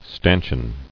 [stan·chion]